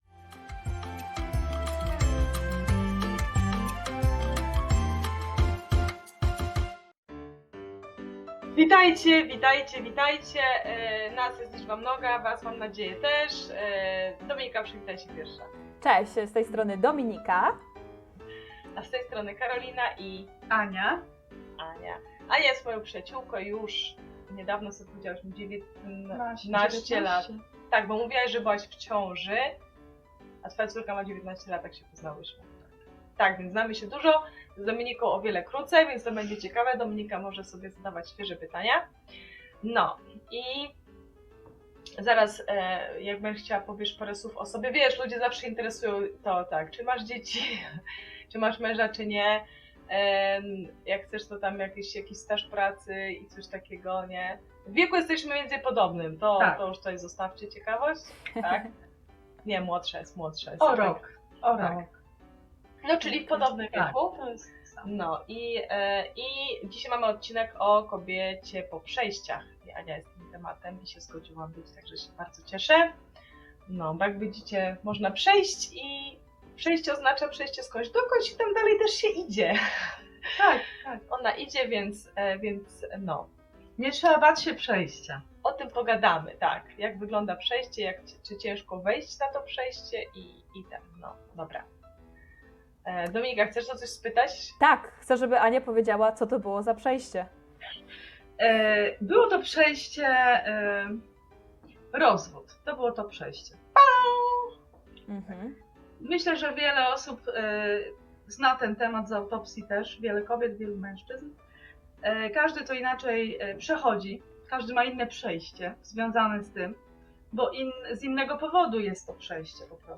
Wywiad z upadłym chrześcijaninem cz.1 69